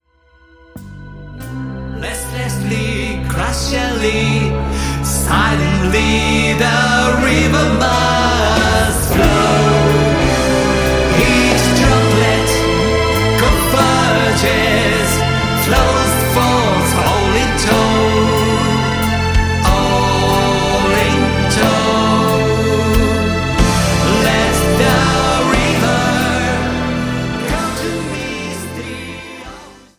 ジャンル Progressive
アンビエント
インストゥルメンタル
シンフォニック系
多重録音